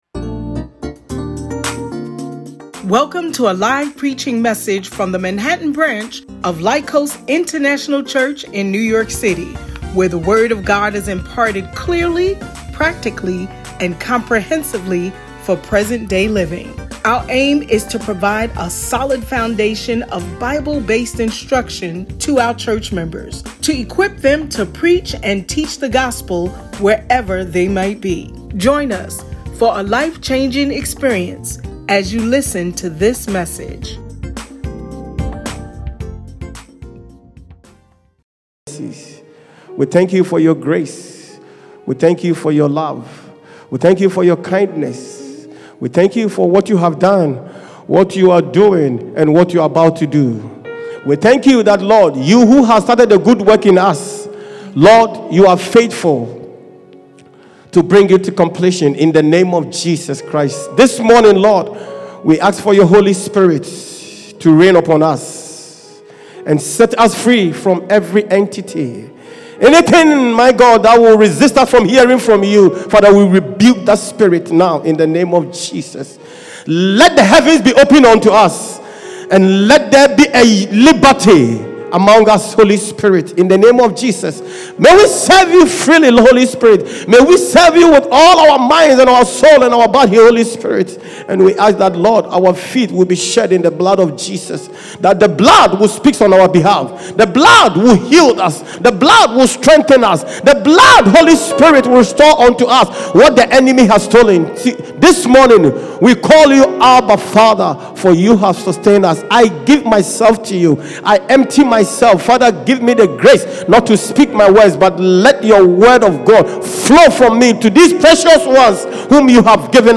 Family Gathering Service Sermon